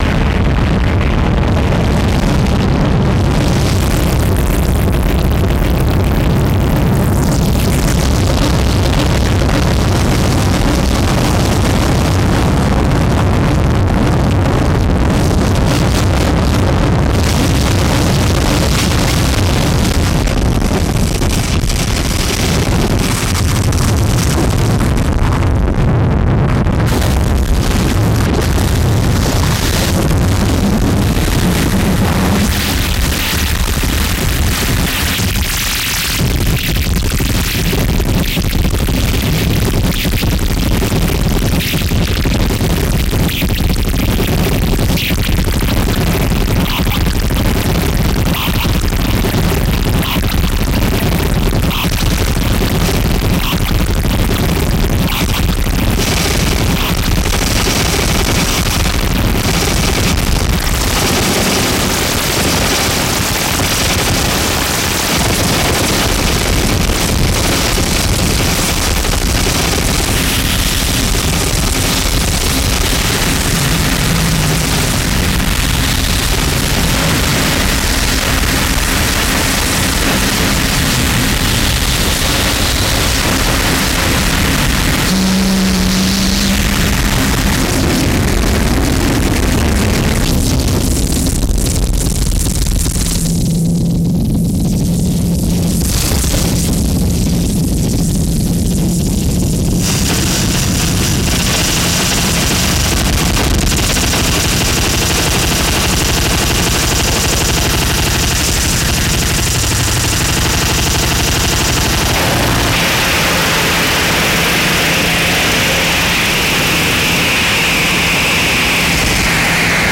• Genre: Japanese Noise